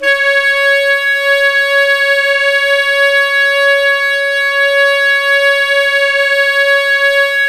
SAX_smc#5ax   23.wav